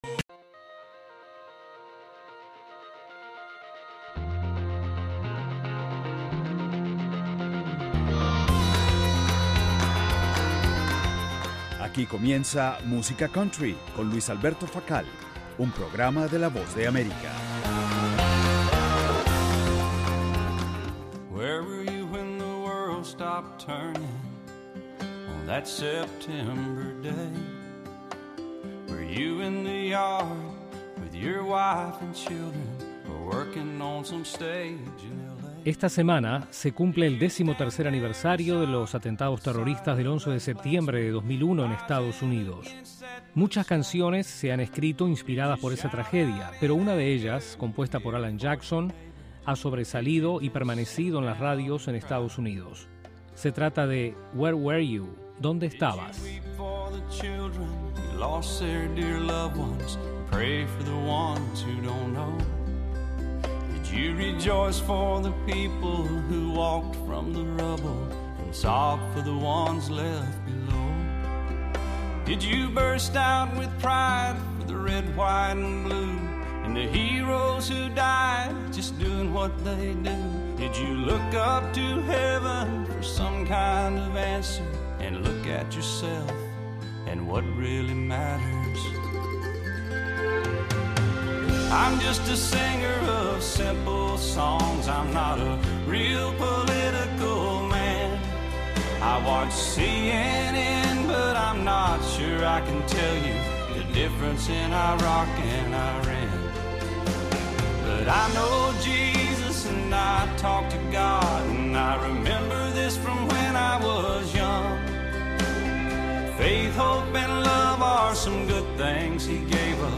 el programa musical